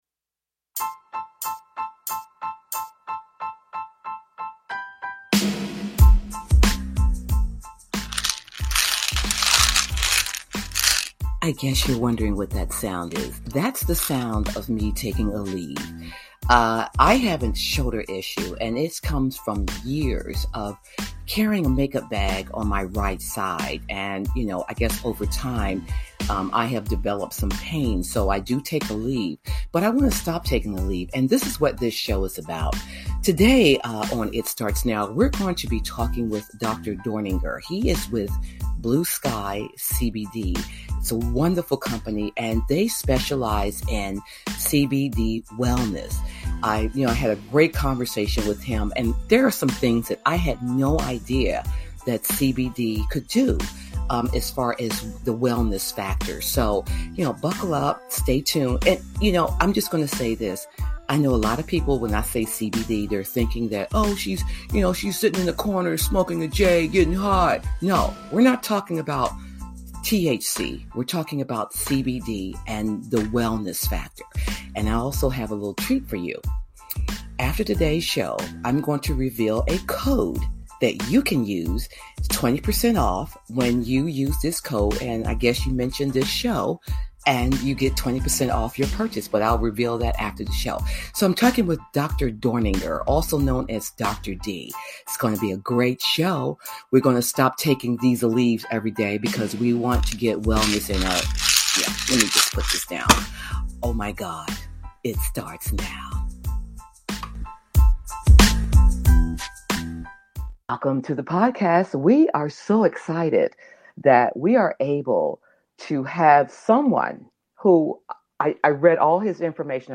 Podcast Bio : is a lively, conversational podcast that flips the script on aging.